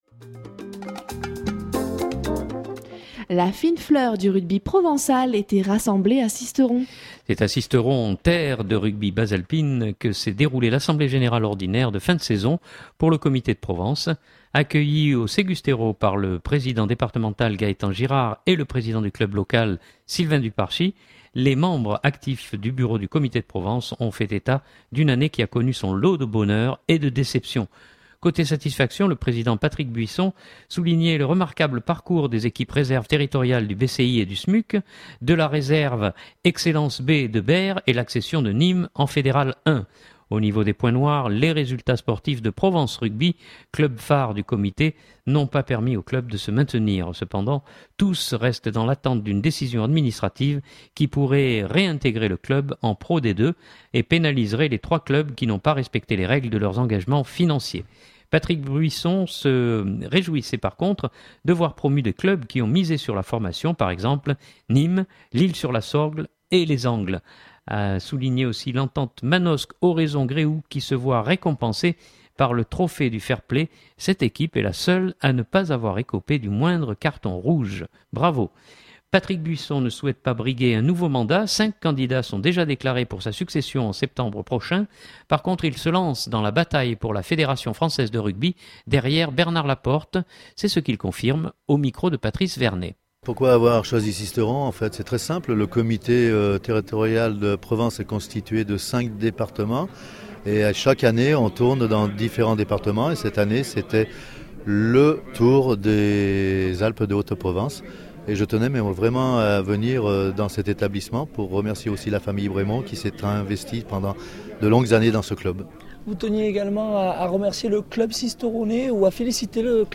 C’est à Sisteron, terre de rugby bas-alpine, que s’est déroulée l’assemblée générale ordinaire de fin de saison pour le Comité de Provence.